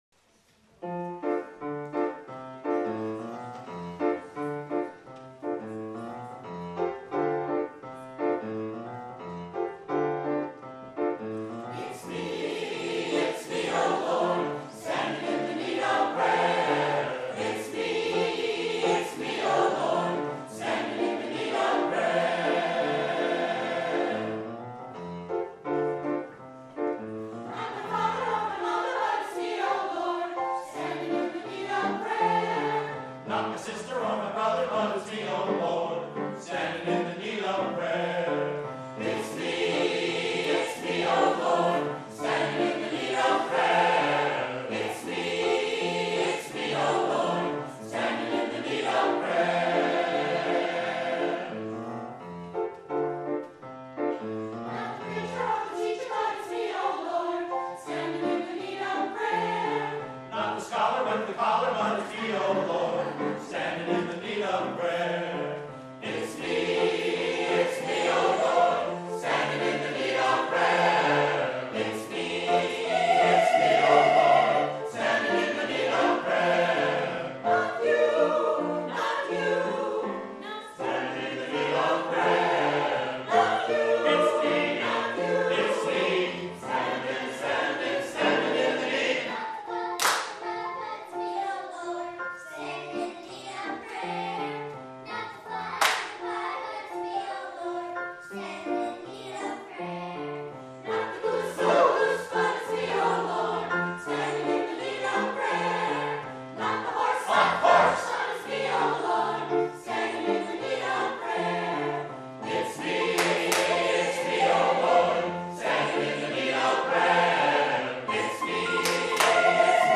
for SATB Chorus, Opt. Children's Chorus, and Piano (2006)